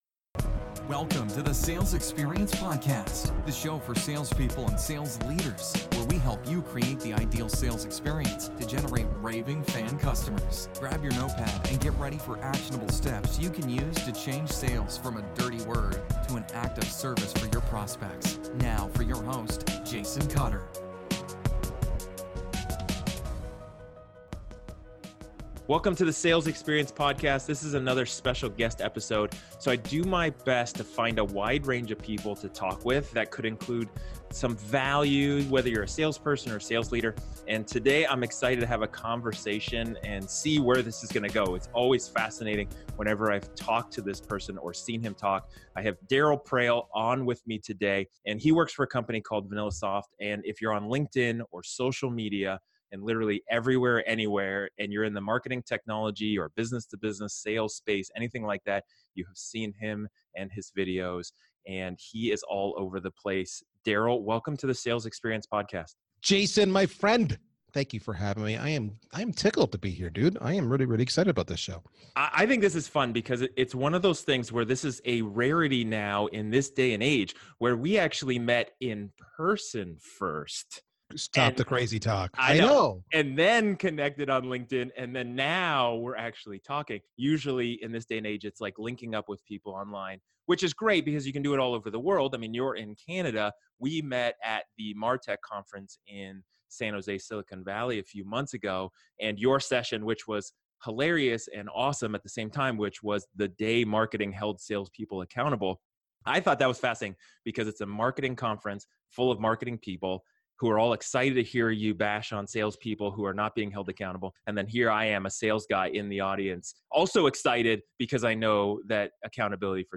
This guest episode felt more like a fireside chat or a (non) argument at the bar about sales scripts.